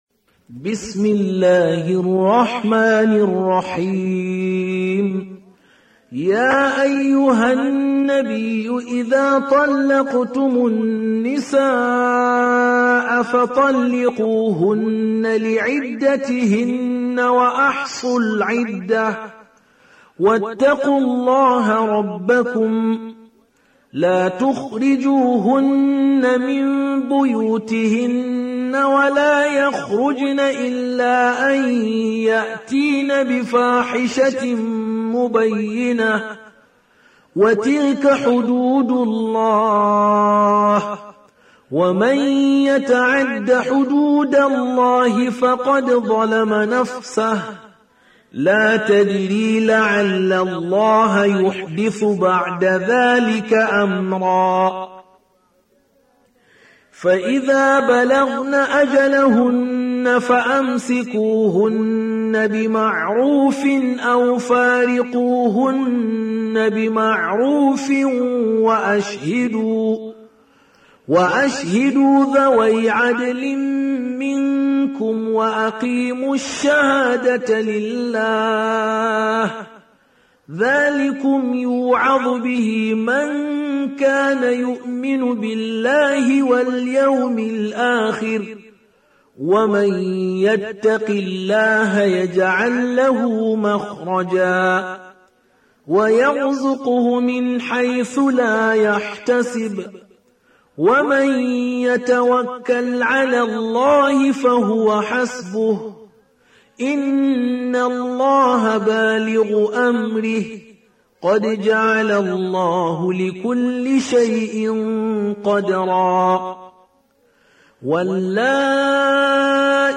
65. Surah At-Tal�q سورة الطلاق Audio Quran Tarteel Recitation
Surah Sequence تتابع السورة Download Surah حمّل السورة Reciting Murattalah Audio for 65.